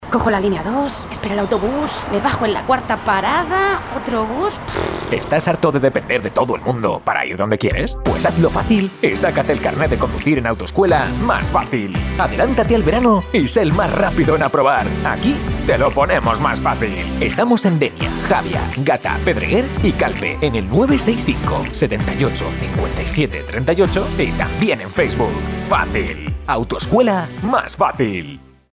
Cuña para una autoescuela de Alicante.